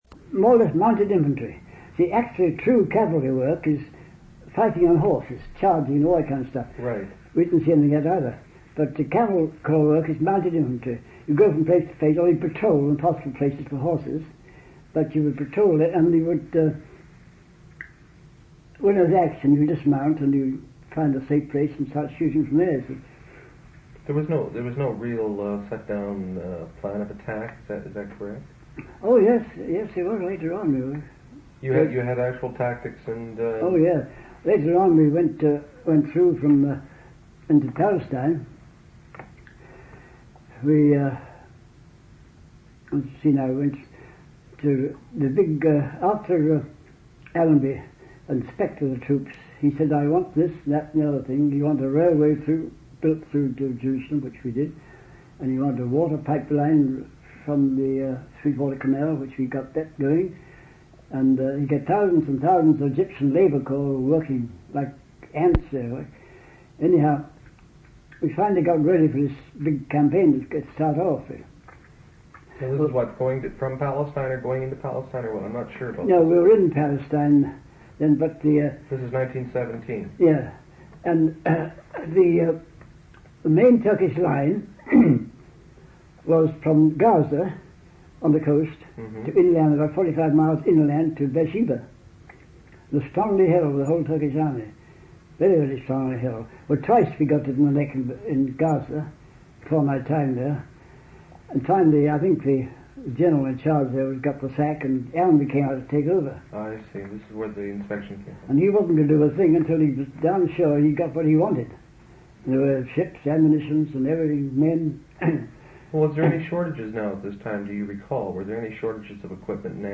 Interview took place on August 18, 1978.